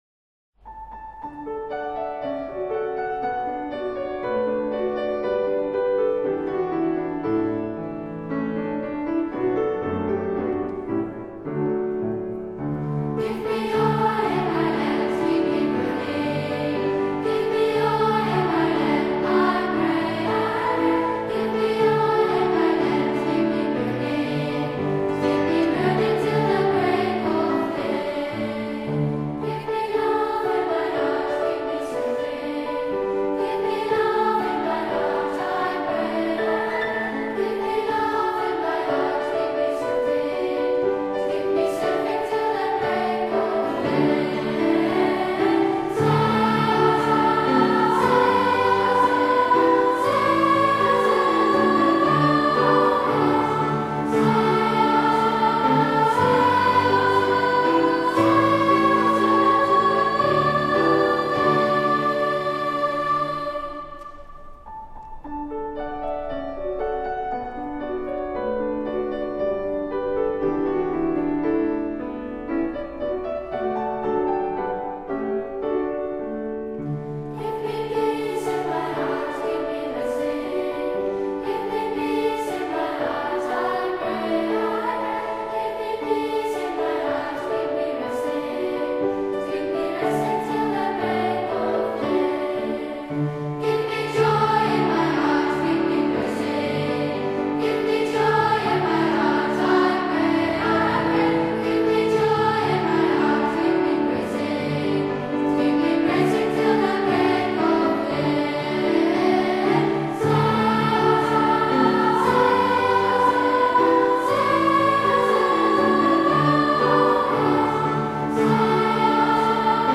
Ter herinnerring aan het 60 jarig jubileumconcert van leerlingen van het Driestarcollege in de grote kerk te Gouda
piano